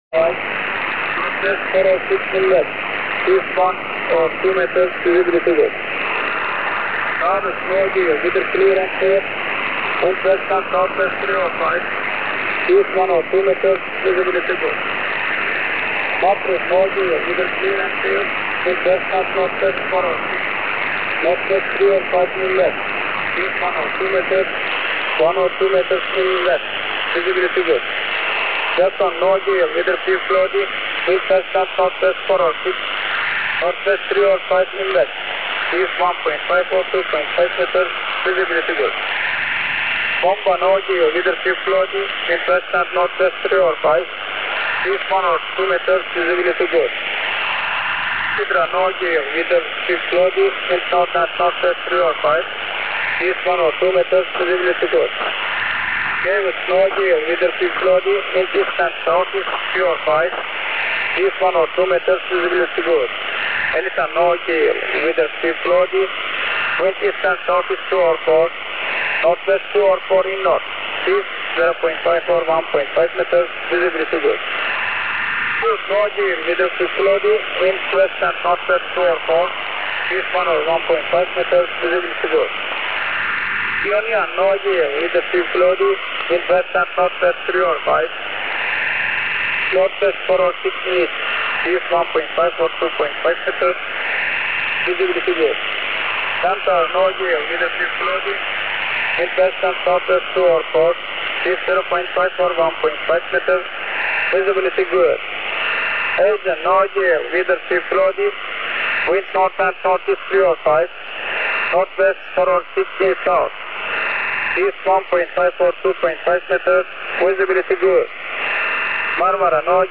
Начало » Записи » Записи радиопереговоров - корабли и береговые станции
BANDIRMA (Турция) прогноз погоды для районов Средиземного и Черного морей. 13128 кГц, ISTANBUL TURK RADIO